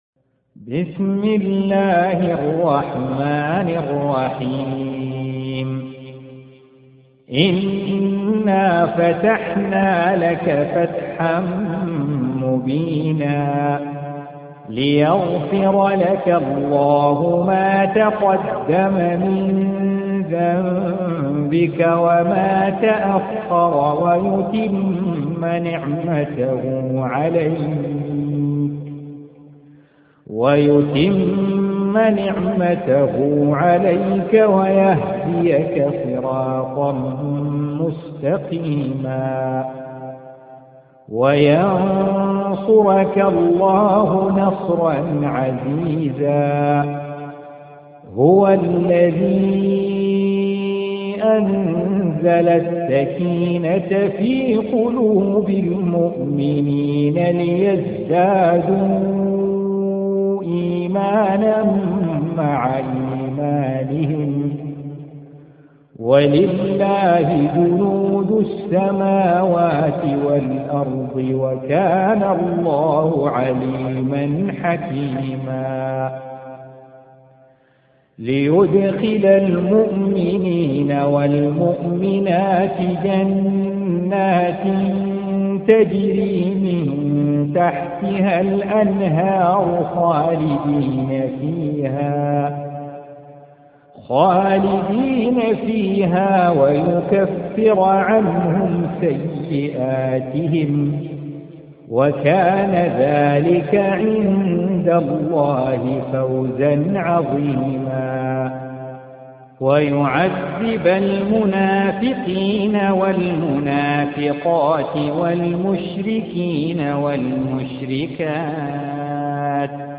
48. Surah Al-Fath سورة الفتح Audio Quran Tarteel Recitation
حفص عن عاصم Hafs for Assem
Surah Sequence تتابع السورة Download Surah حمّل السورة Reciting Murattalah Audio for 48. Surah Al-Fath سورة الفتح N.B *Surah Includes Al-Basmalah Reciters Sequents تتابع التلاوات Reciters Repeats تكرار التلاوات